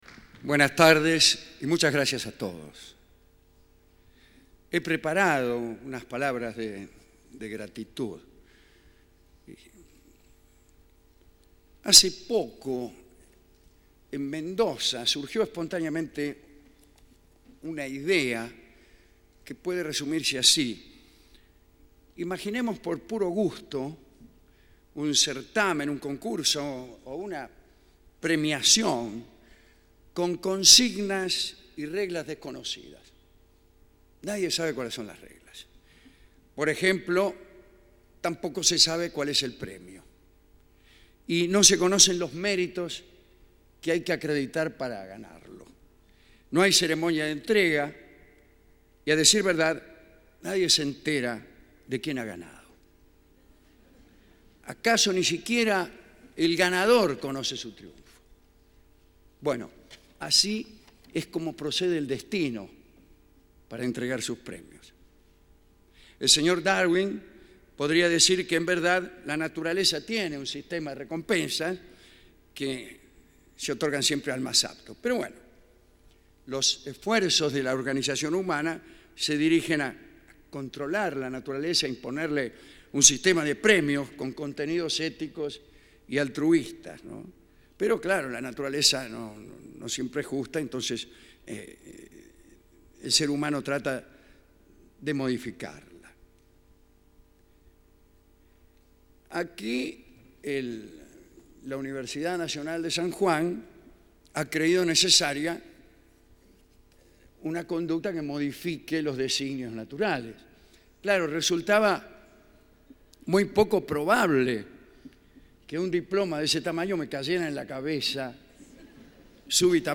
Charla-Alejandro-Dolina-en-la-UNSJ.mp3